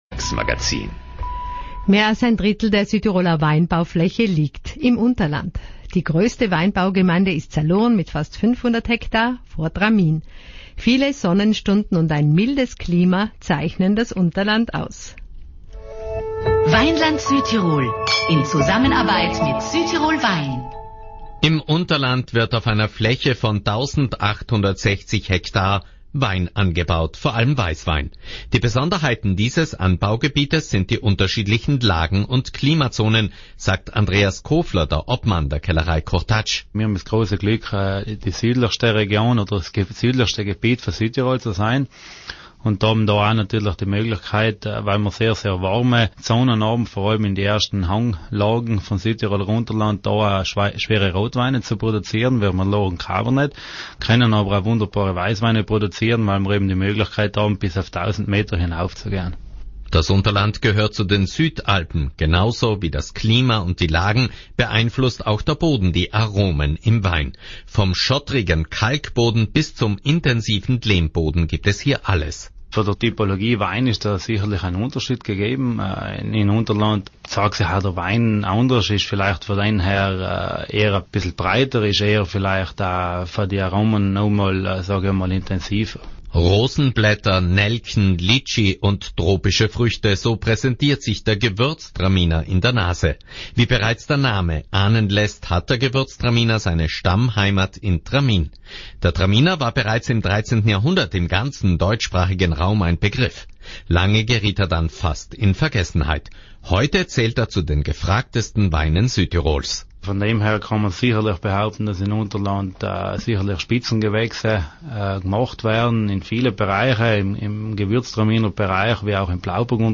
Im Unterland wird auf einer Fläche von 1.860 ha Wein angebaut. Hier wachsen schwerere Rotweine und Weißweine: die Vielseitigkeit der Weine aus diesem Gebiet der Südalpen liegt an den unterschiedlichen Böden, Lagen und Klimazonen. Ein Radiobeitrag